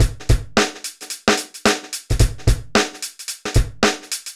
Index of /90_sSampleCDs/AKAI S6000 CD-ROM - Volume 4/Others-Loop/BPM110_Others2